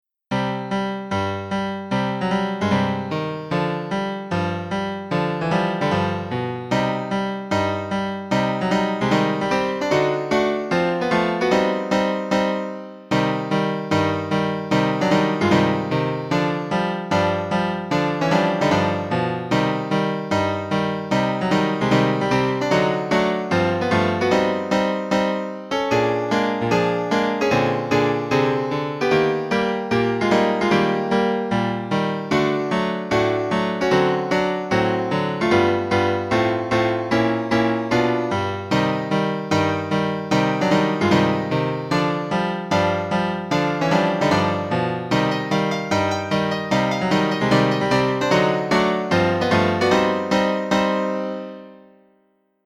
フィンランドのフォークダンスのピアノアレンジ。